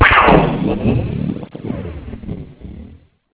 thunder.au